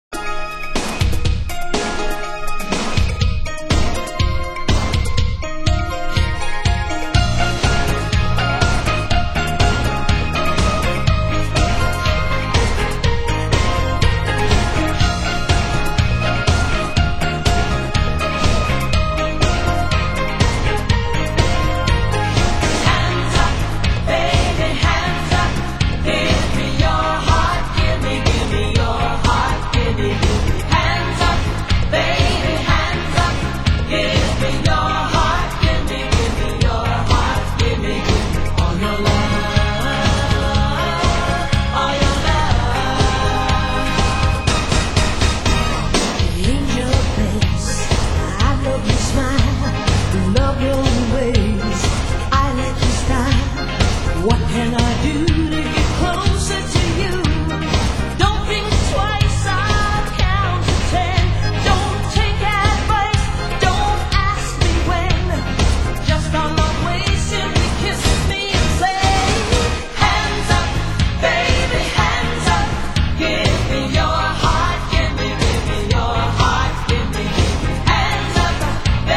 Genre: Synth Pop